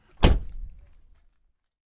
沃尔沃740 " 卡门关闭
描述：关上沃尔沃740的门
Tag: 关闭 外观 轰的一声 沃尔沃